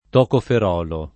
tocoferolo [ tokofer 0 lo ] s. m. (chim.)